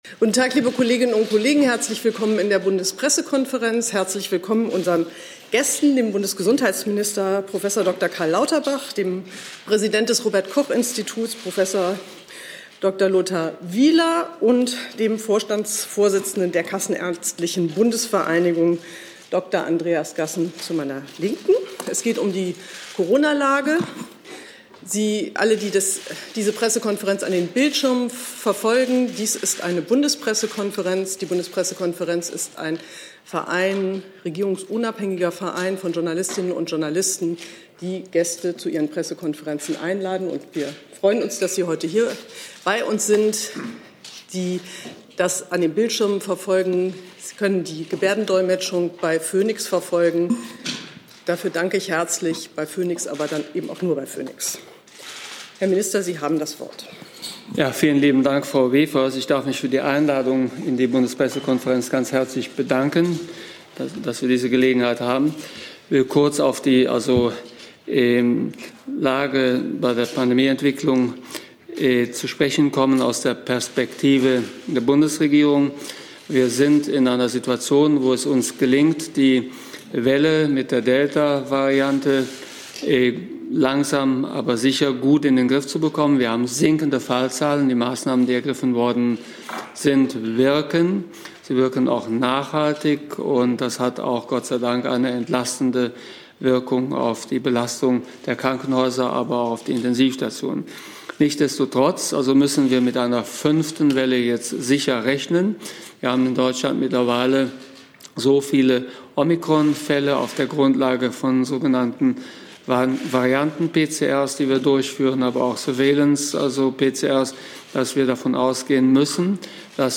"Corona-Lage" in der Bundespressekonferenz mit - Prof. Dr. Karl Lauterbach, Bundesminister für Gesundheit (SPD) - Prof. Dr. Lothar H. Wieler, Präsident Robert Koch-Institut (RKI) -